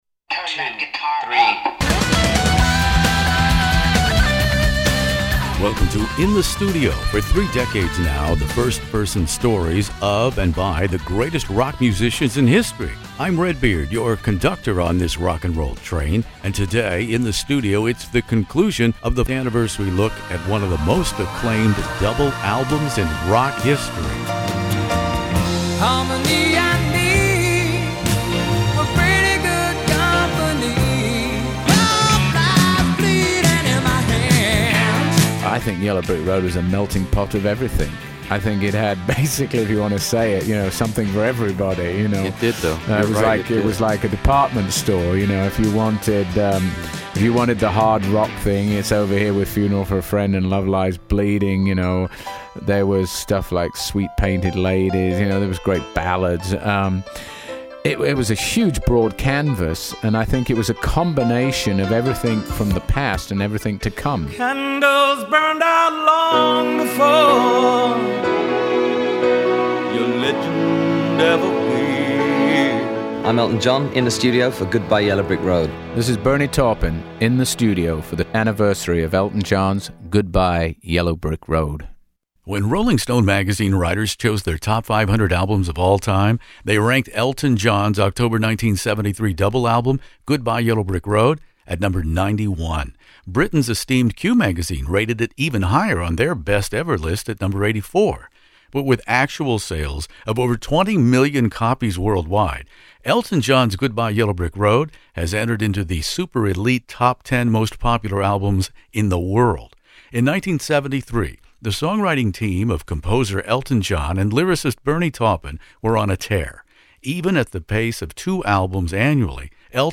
This is the conclusion of my classic rock interviews with Sir Elton John and lyricist Bernie Taupin about the blockbuster double album Goodbye Yellow Brick Road, which includes a segment on the remake of “Candle in the Wind ’97”, the tribute written in less than a week by Taupin and performed live by Elton at the funeral of Princess Diana to a broadcast audience estimated at 2.2 billion viewers.